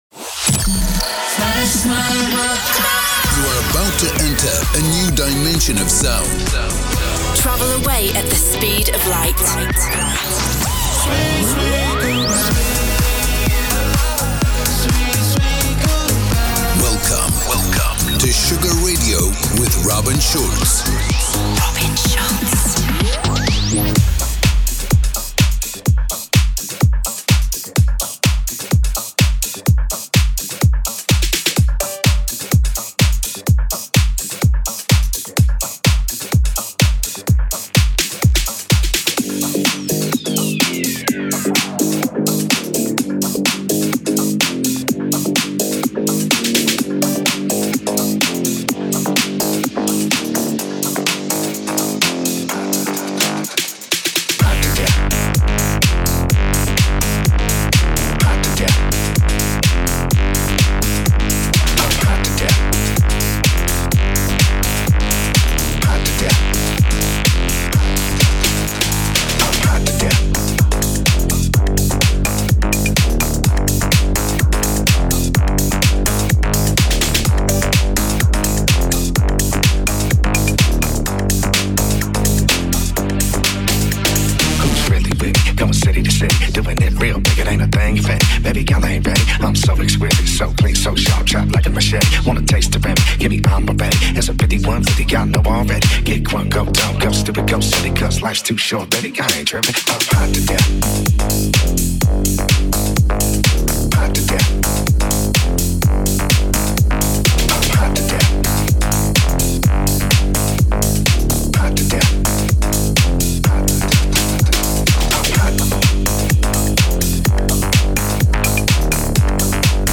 music DJ Mix in MP3 format
Genre: Electro Pop